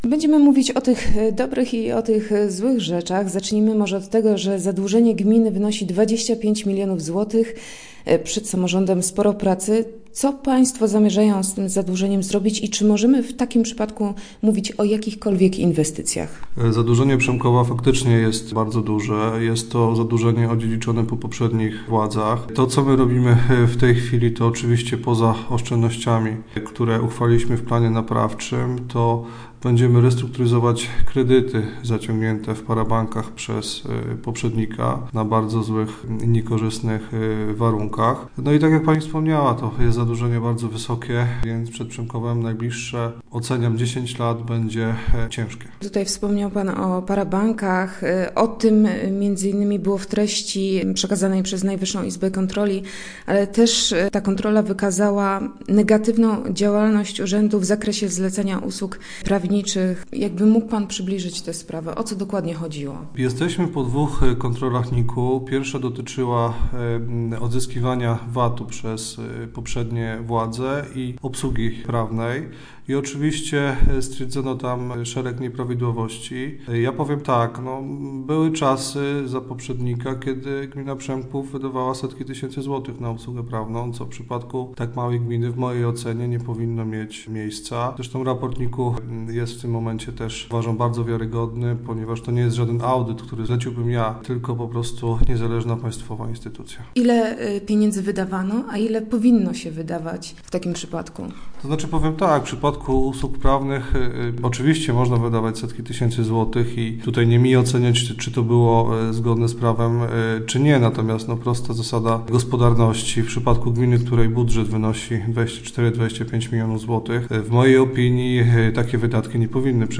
jerzy_szczupak.jpgGościem piątkowych Rozmów Elki był Jerzy Szczupak. Z burmistrzem Przemkowa rozmawialiśmy na temat planów wyjścia gminy z zadłużenia, które obecnie wynosi 25 mln złotych. Pytaliśmy również o kontrolę NIK, remonty dróg i o Legnicką Specjalną Strefę Ekonomiczną.